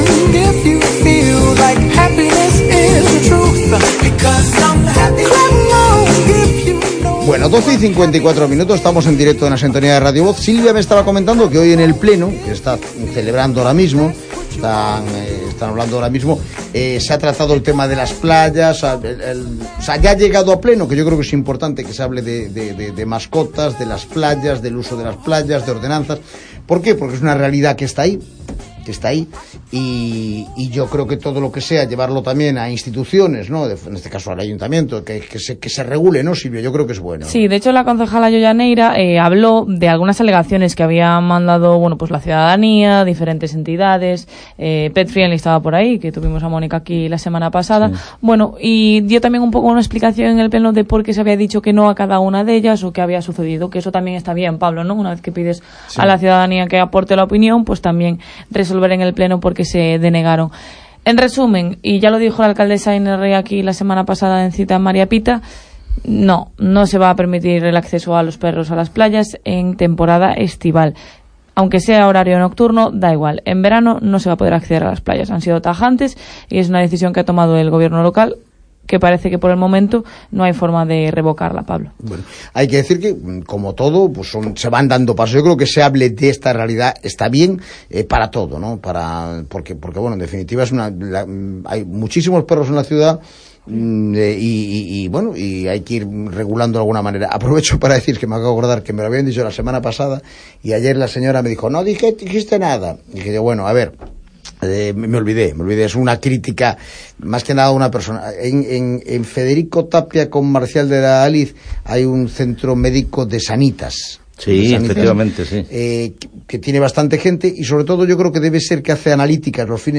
Montegatto en los medios VOCES DE A CORUÑA Nueva tertulia canina en Voces ce A Coruña